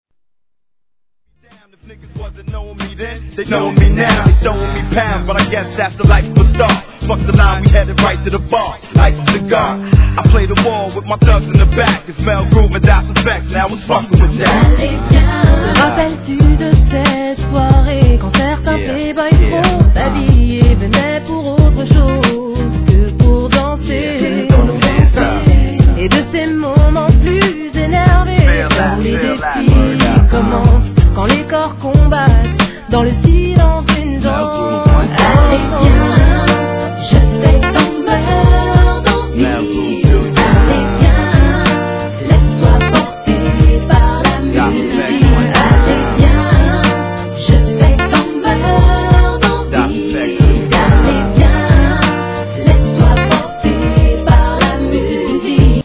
HIP HOP/R&B
アコースティックなトラックが哀愁漂うナイスチューンです！